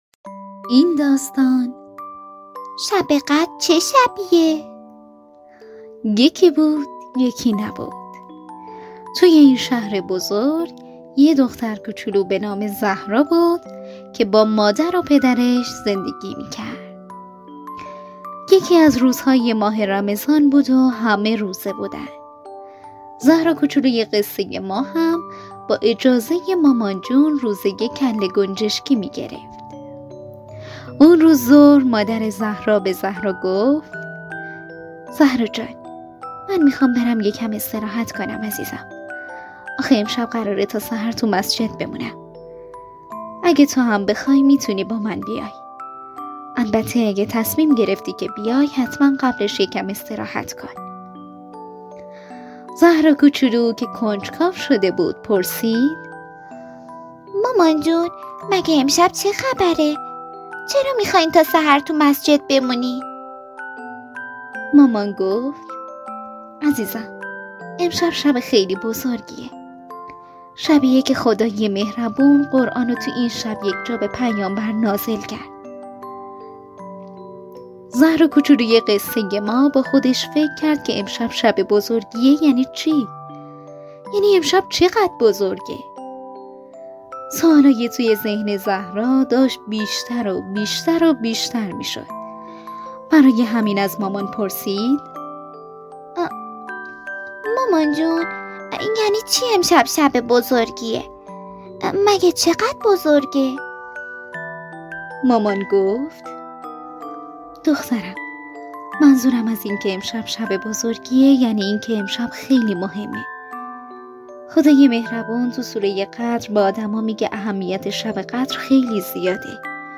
داستان صوتی ویژه کودکان با موضوع شب قدر